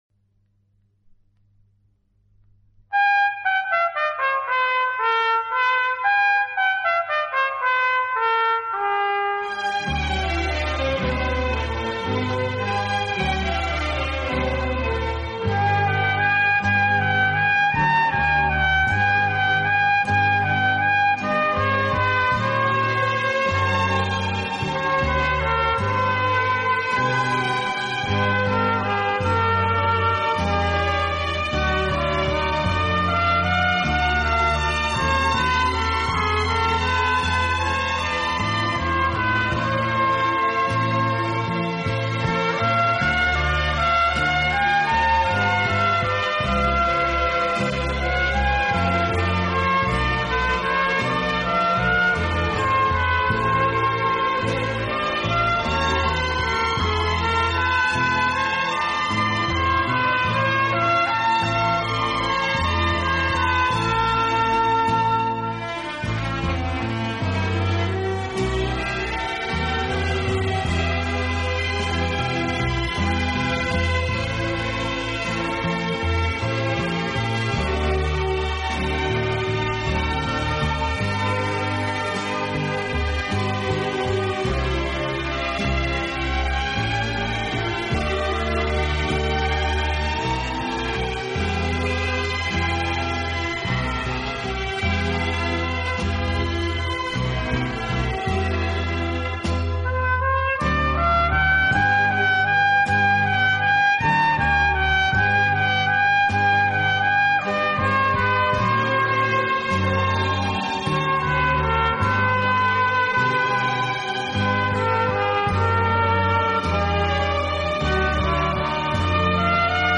【轻音乐】
体，曲风浪漫、优雅，令人聆听時如感轻风拂面，丝丝柔情触动心扉，充分领略